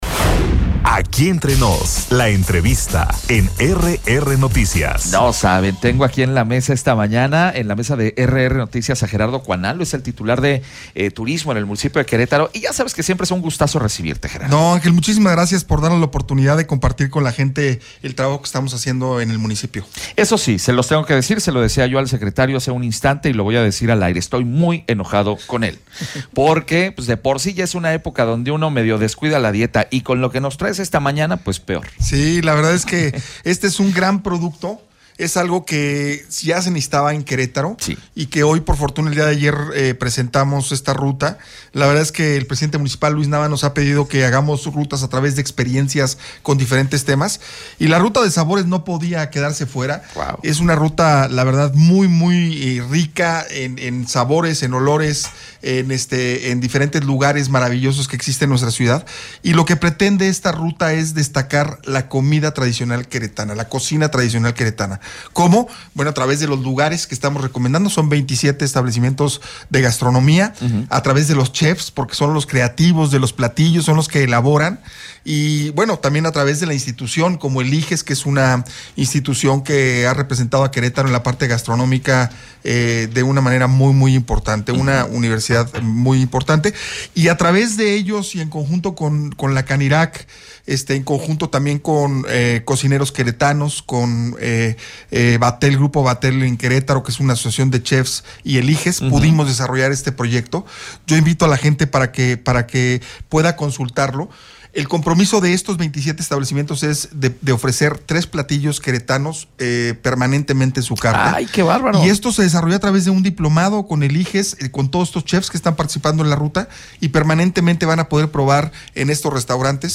En entrevista Gerardo Cuanalo, secretario de turismo del municipio de Querétaro, nos presenta la nueva ruta: «Sabores»
ENTREVISTA-GERARDO-CUANALO-SANTOS-SECRETARIO-DE-TURISMO-MUNICIPAL.mp3